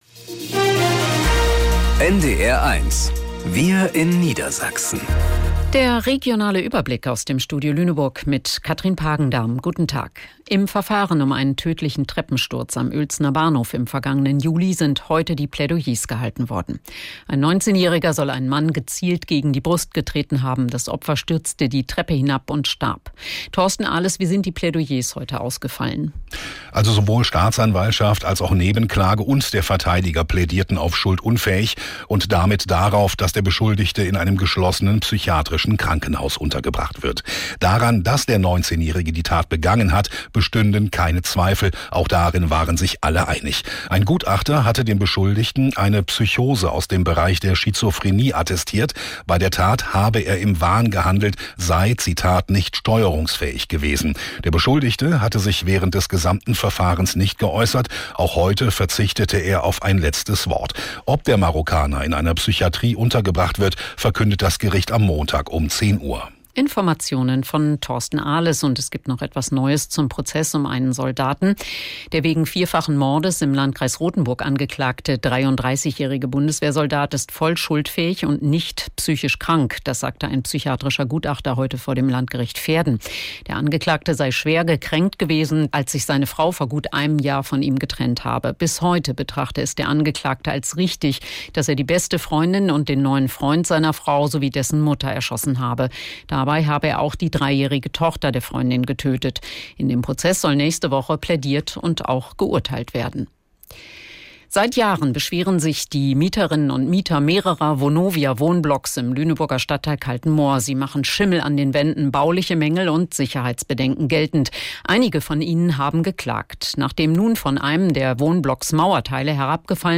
Wir in Niedersachsen - aus dem Studio Lüneburg | Nachrichten